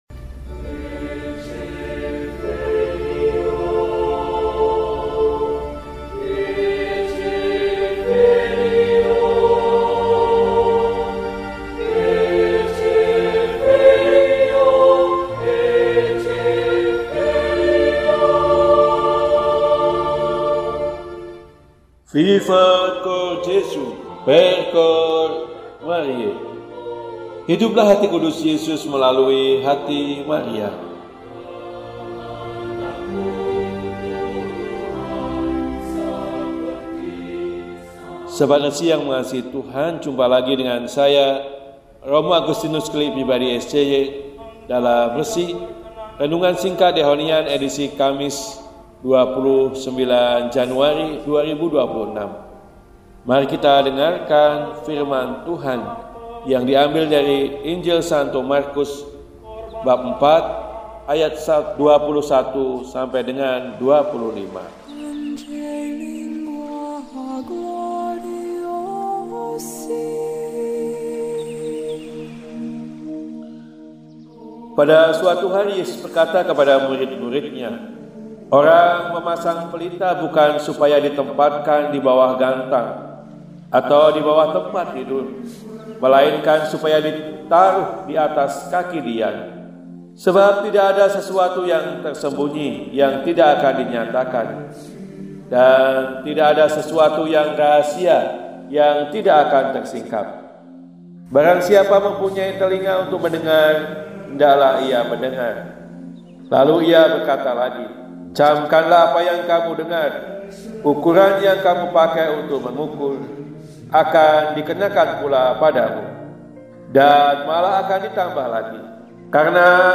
Kamis, 29 Januari 2026 – Hari Biasa Pekan III – RESI (Renungan Singkat) DEHONIAN